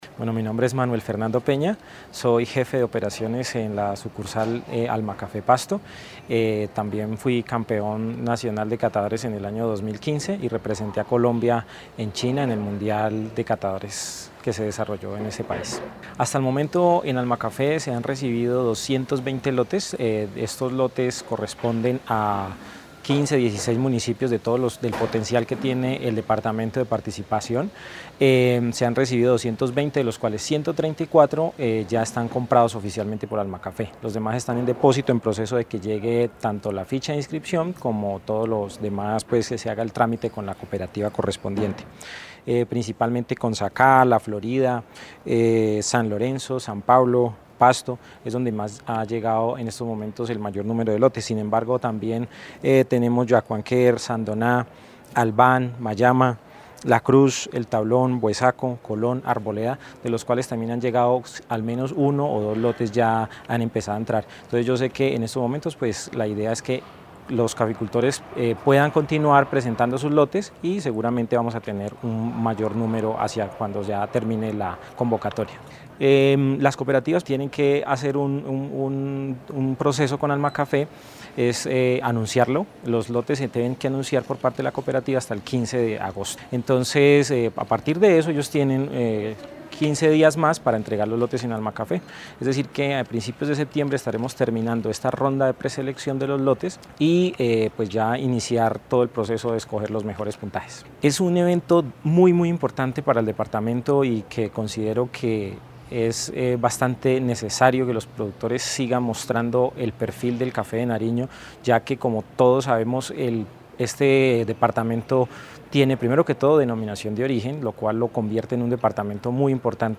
Entrevistas a: